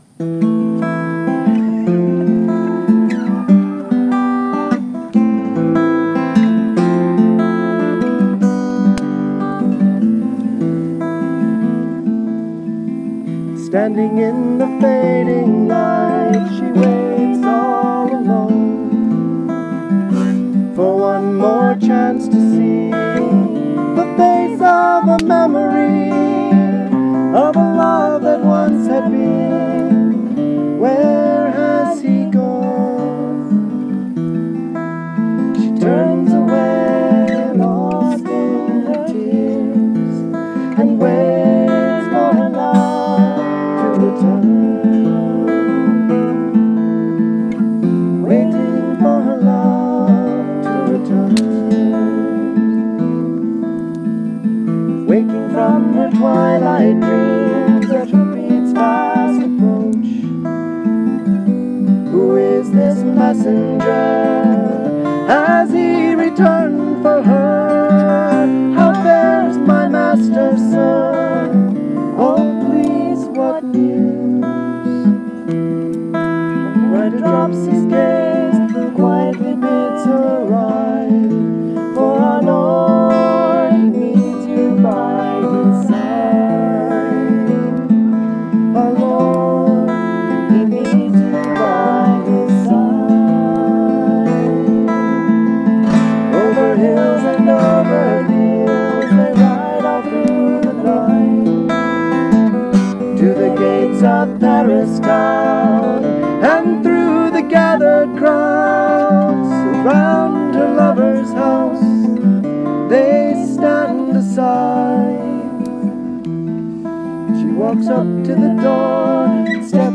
HeloiseAndAbelard live recording, Pennsic 43. Atlantian Bardic Circle.